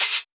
Dilla Shaker 09.wav